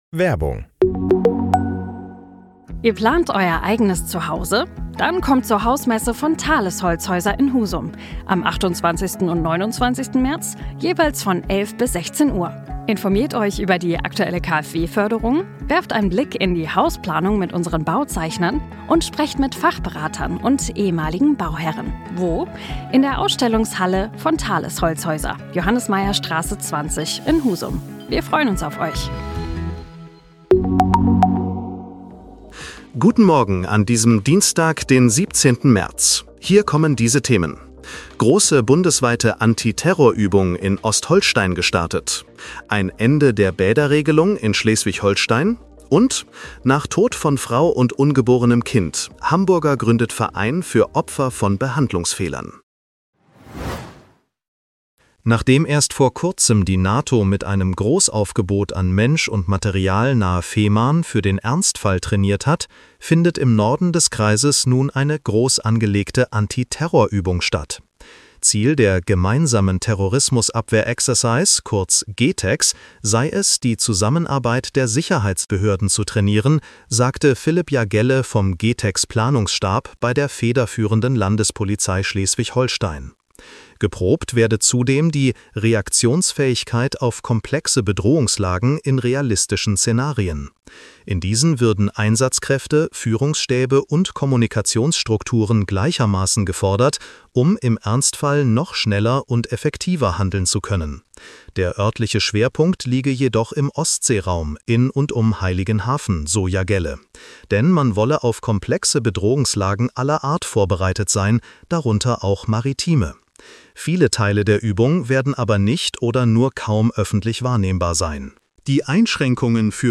Nachrichten-Podcast bekommst Du ab 7:30 Uhr die wichtigsten